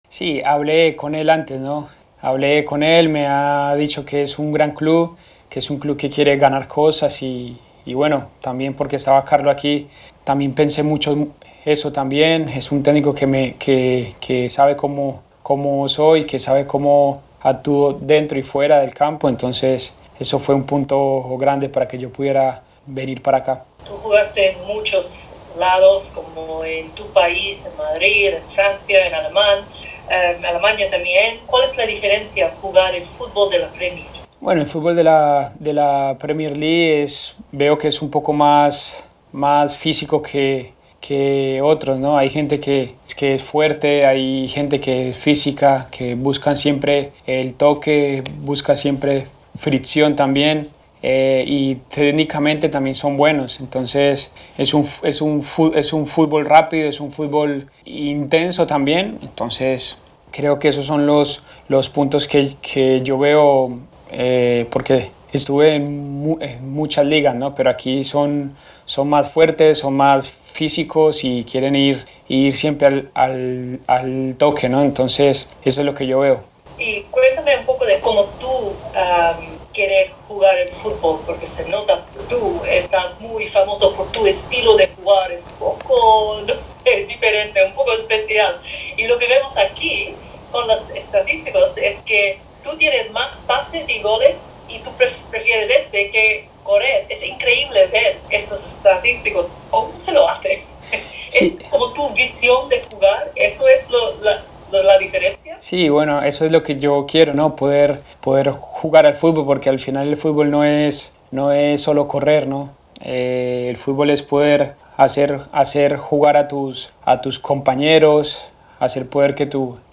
(James Rodríguez, en entrevista con la página web del Everton)